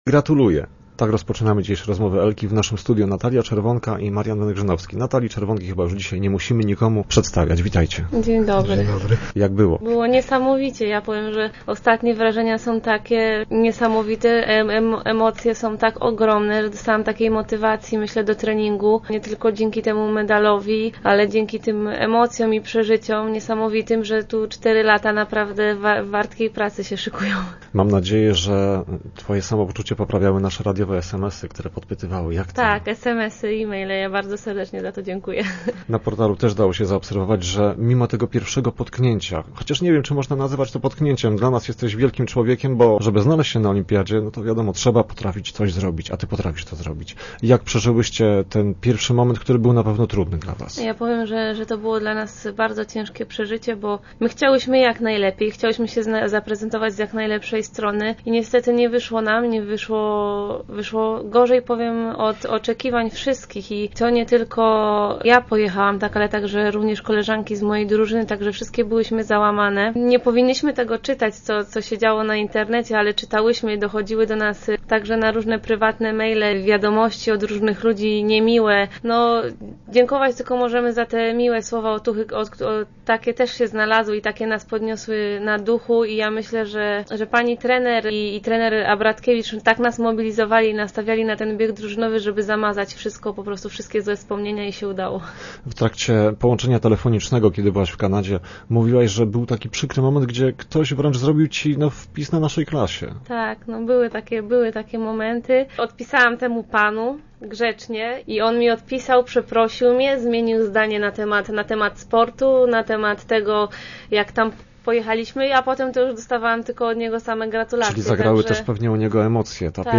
Jak było w Kanadzie, co sprawiło jej przykrość, a co radość. Z lubińską olimpijką rozmawialiśmy o tym w Rozmowach Elki.